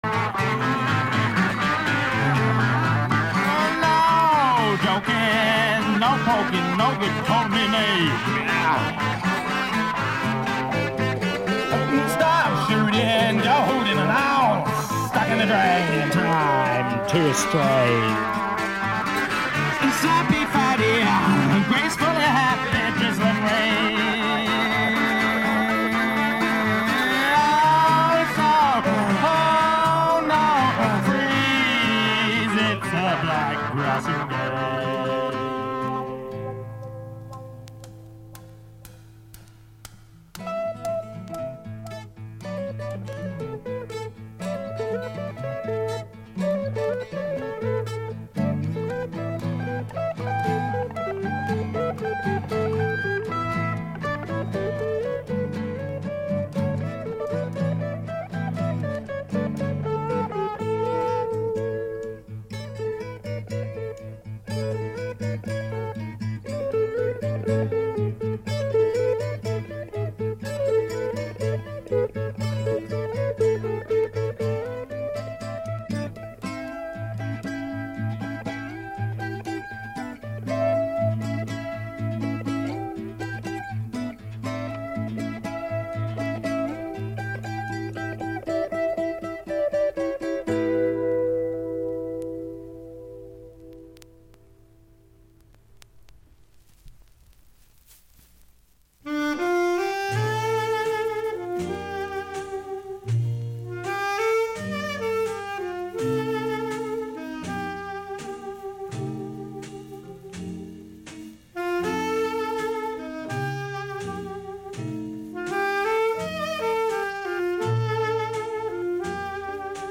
i play old scratchy records